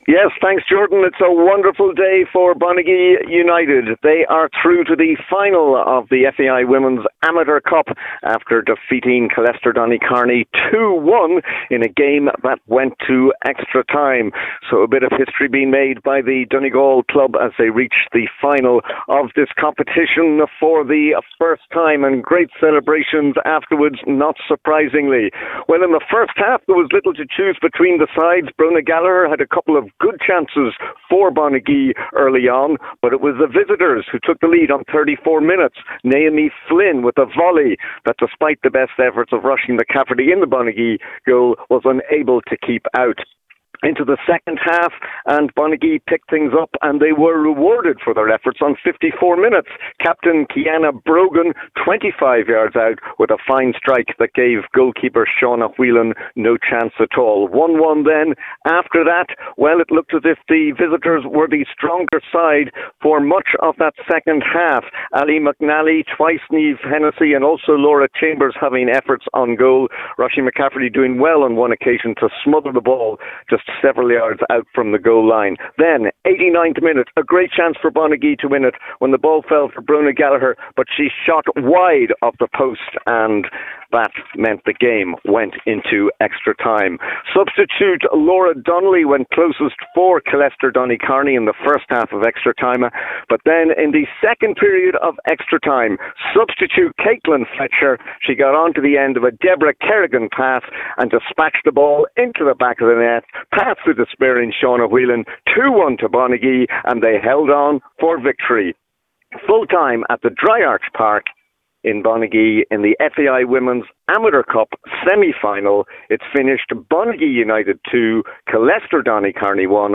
FT Report & Reaction as Bonagee United Ladies reach FAI Amateur Cup final